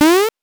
8 bits Elements
powerup_23.wav